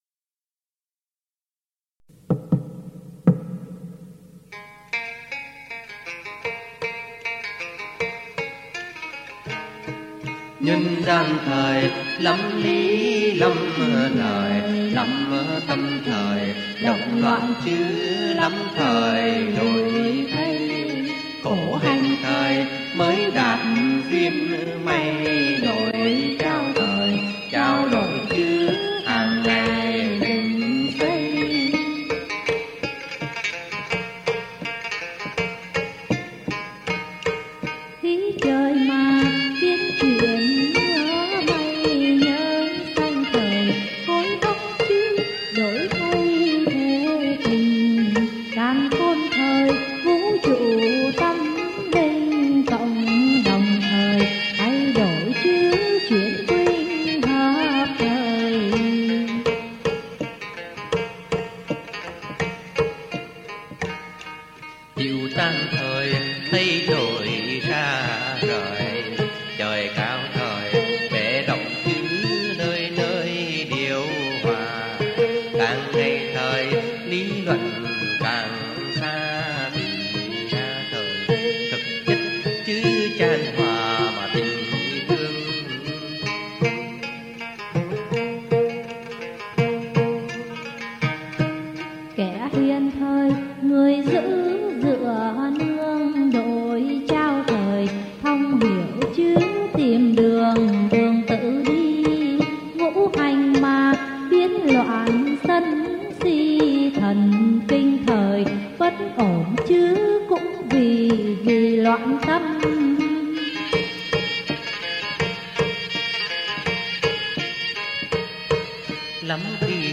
theo điệu Trống Quân miền Bắc